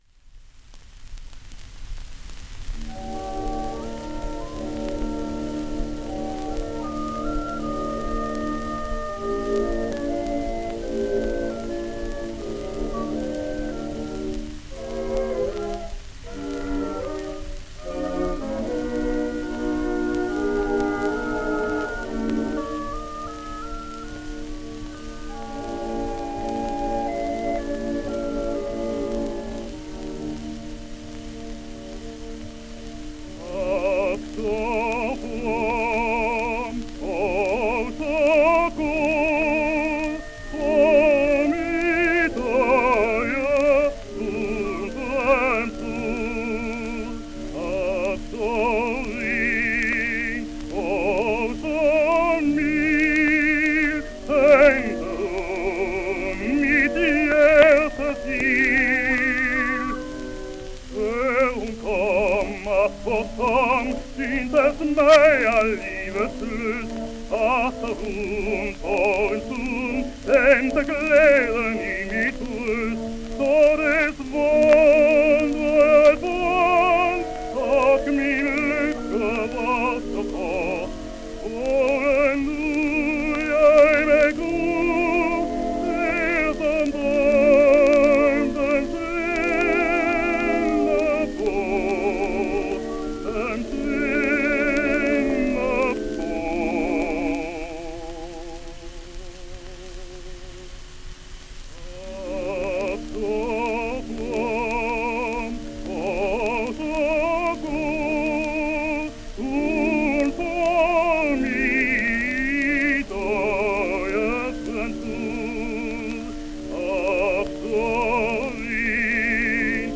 Well, he may not have learned a whole lot from De Lucia, but he certainly sang the same tessitura – tenor arias in baritone key!